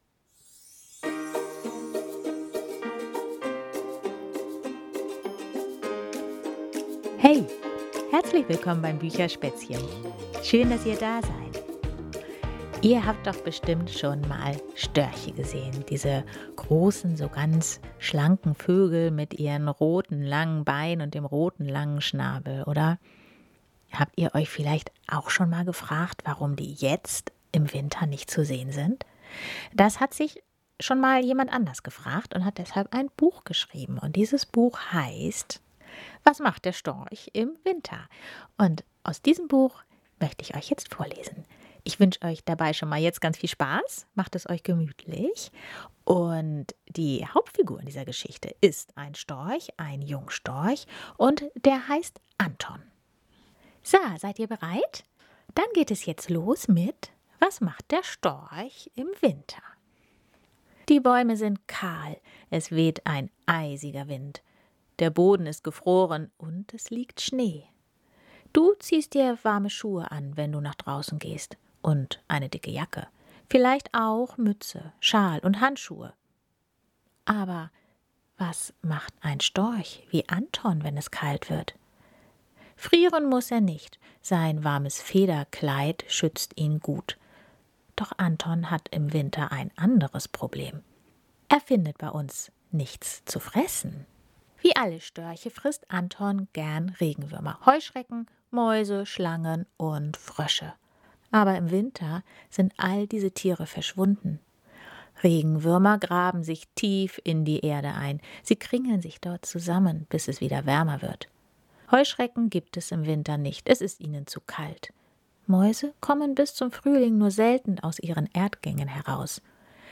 Eine Sachgeschichte mit vielen wertvollen und kindgerechten Informationen